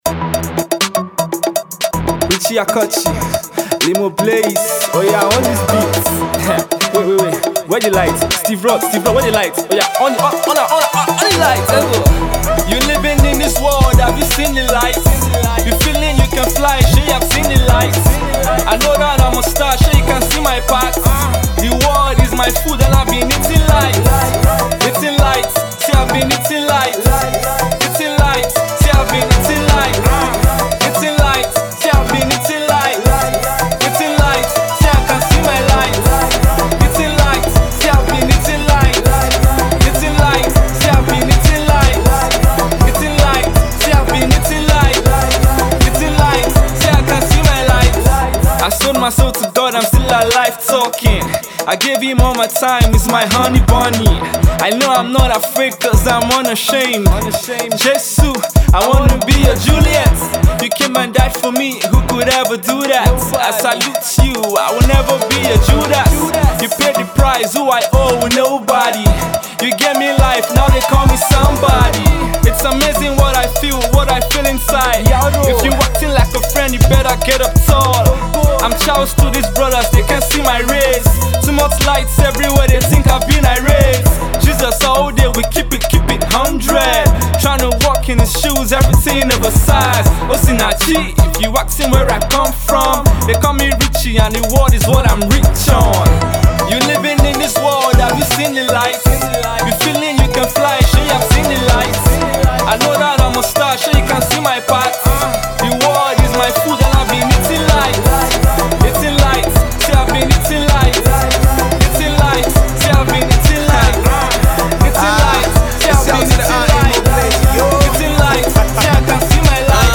kaduna based gospel rapper